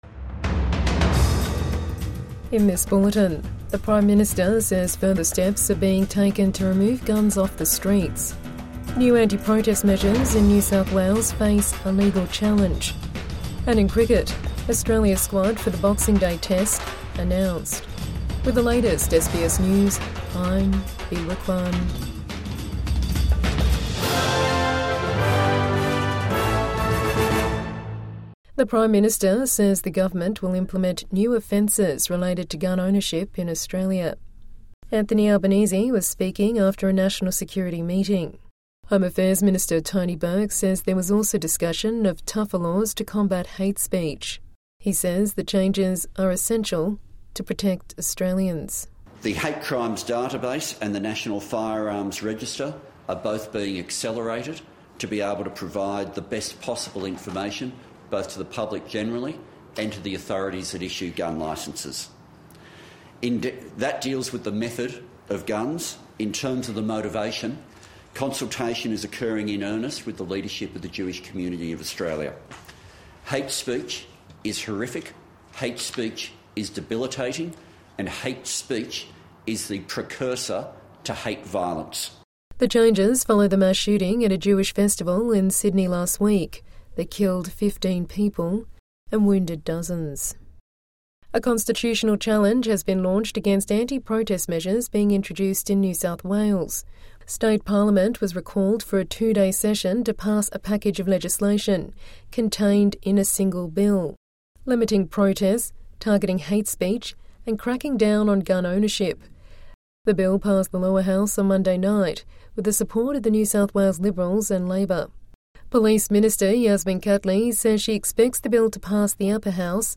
PM says steps taken to remove guns off the streets after Bondi attack | Midday Bulletin 23 December 2025